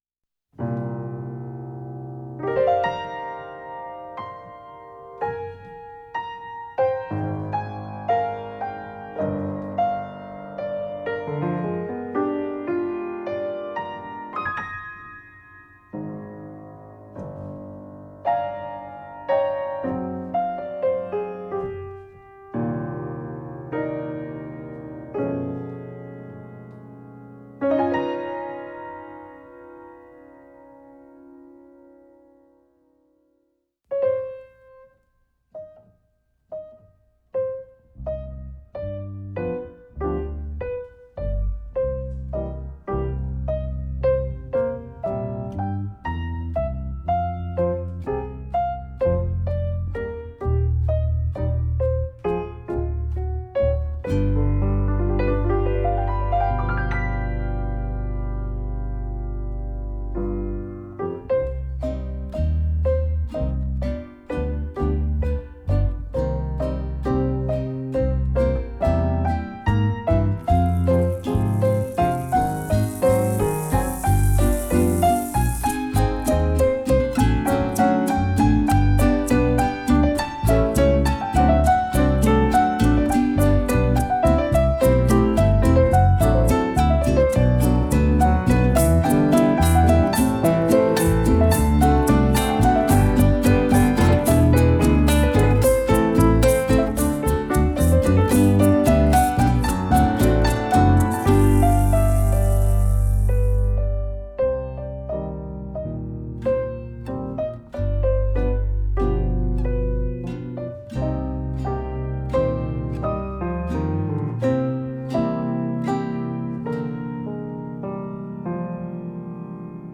merengue venezolano